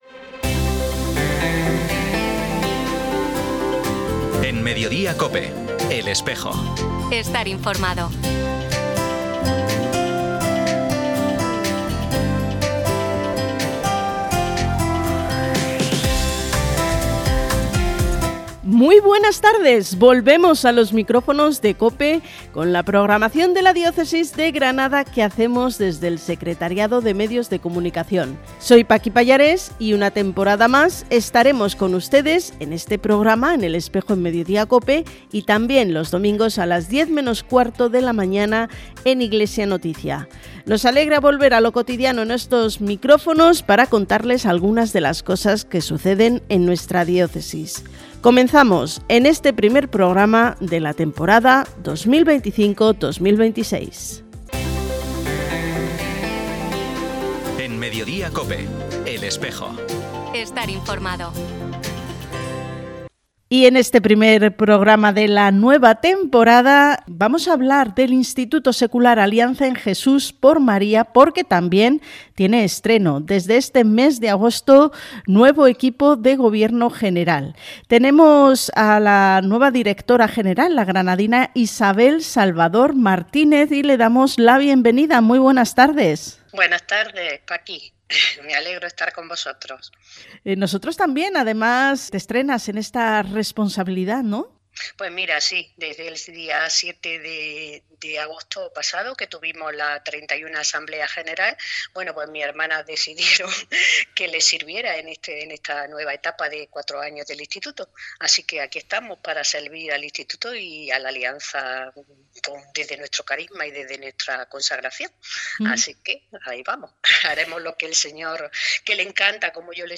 Programa emitido en COPE Granada el 5 de septiembre de 2025.
Hoy 5 de septiembre se ha emitido en COPE Granada y COPE Motril el primer programa de la nueva temporada 2025-2026, con las entrevistas e información de la Iglesia en Granada.